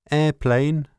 a_airpla.wav